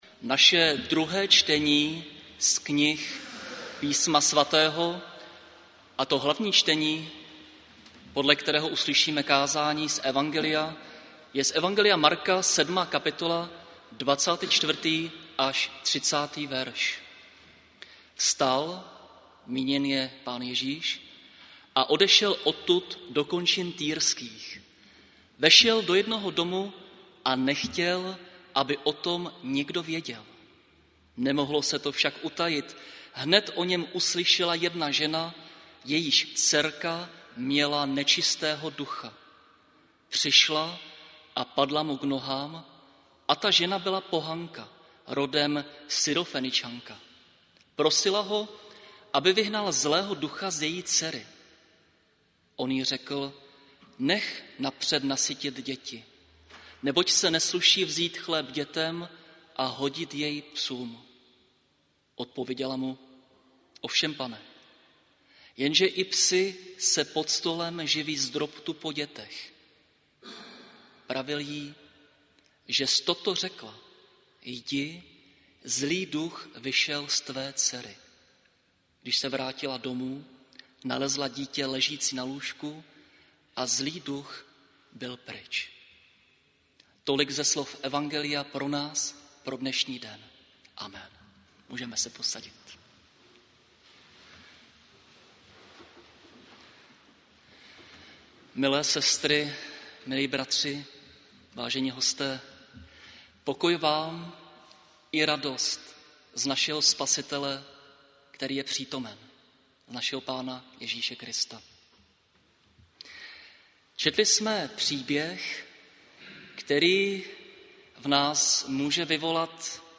Záznam kázání a sborová ohlášení z bohoslužeb.